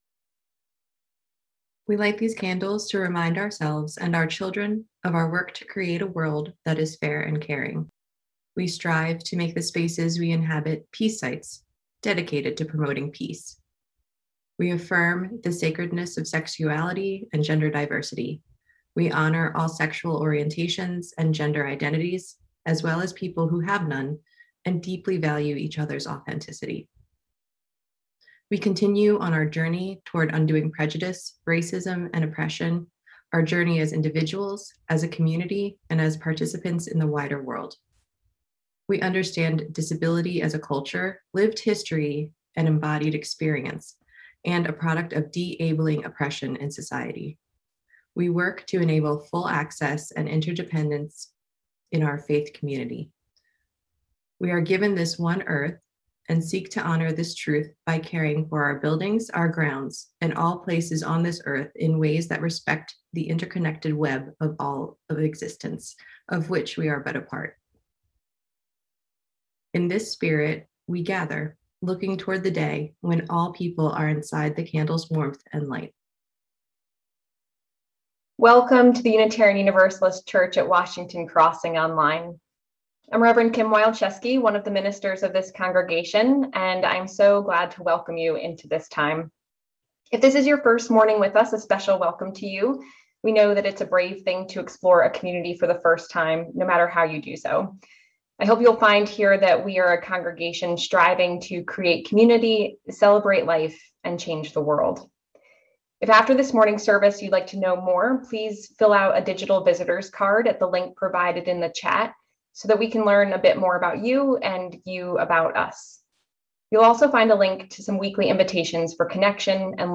Sunday-Service-May-2-2021-Final.mp3